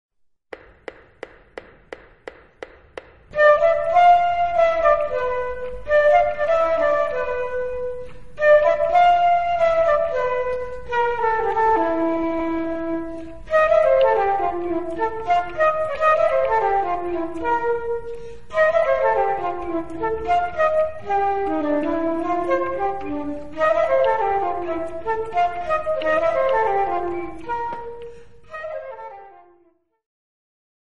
Instrumental Ensembles Flute
A challenge in close harmony playing.
Flute Duet